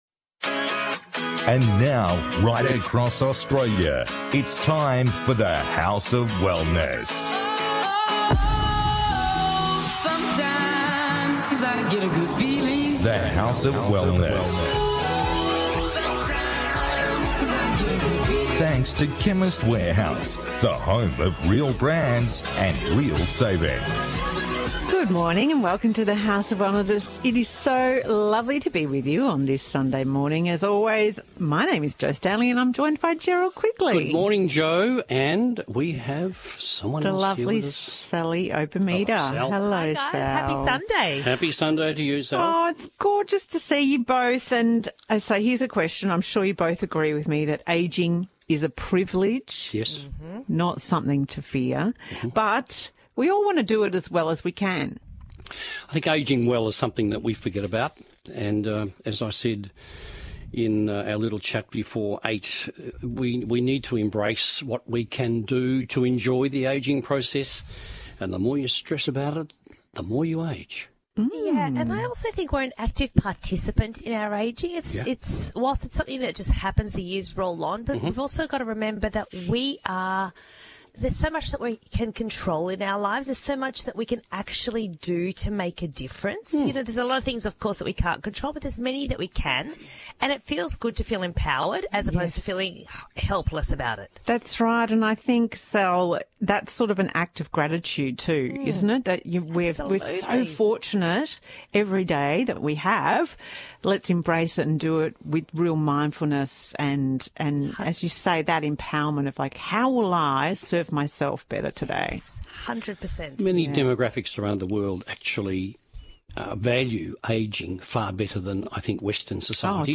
On this week’s The House of Wellness radio show the team discusses: